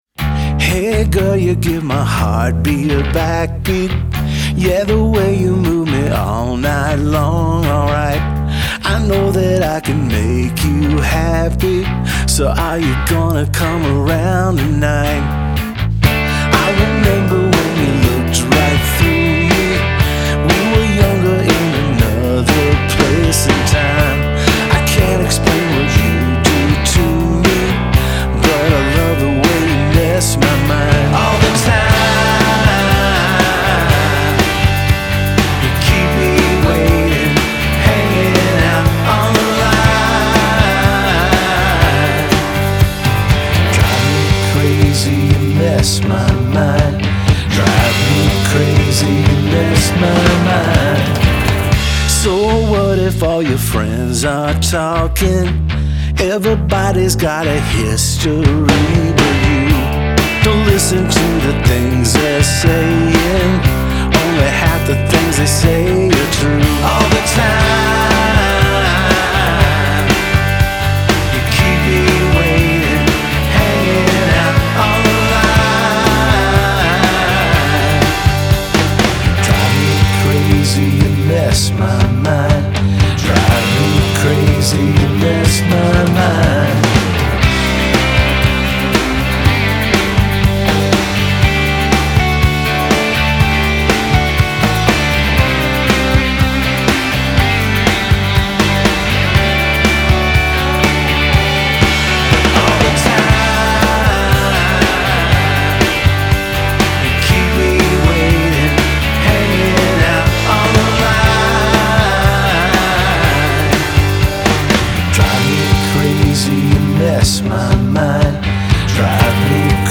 low key verses cut to choruses loaded with harmony vocals